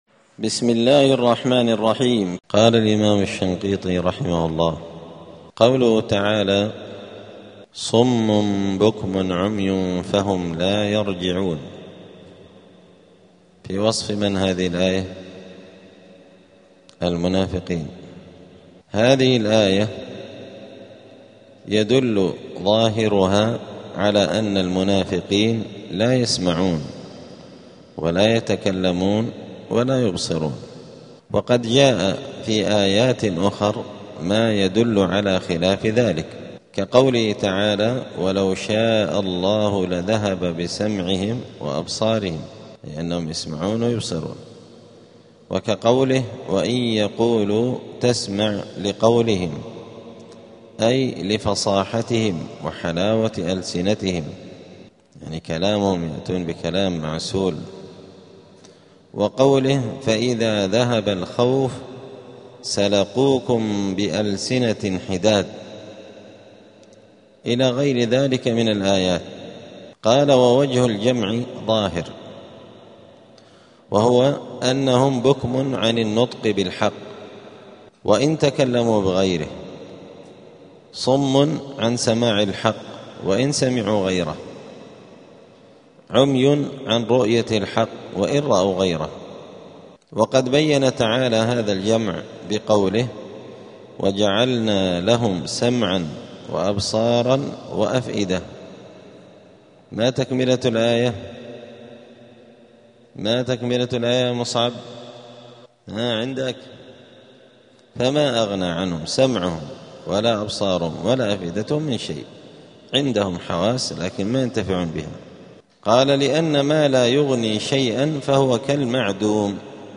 *الدرس الخامس (5) {سورة البقرة}.*